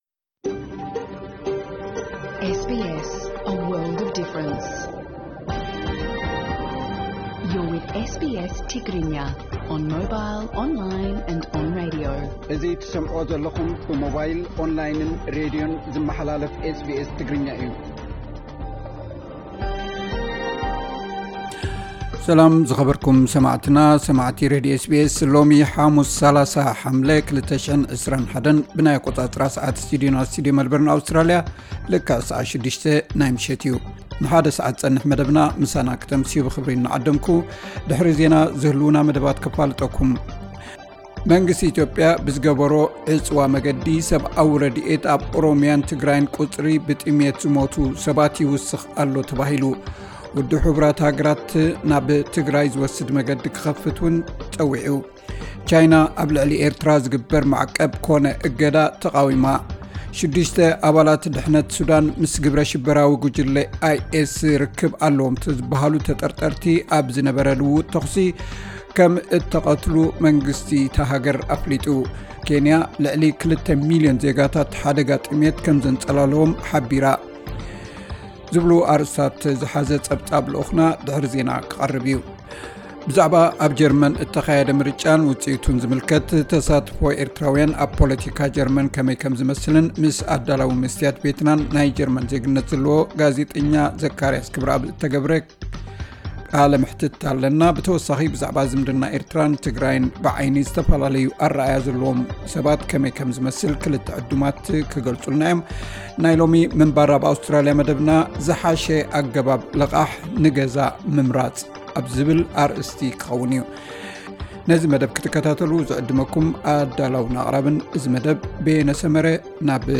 ዕለታዊ ዜና 30 መስከረም 2021 SBS ትግርኛ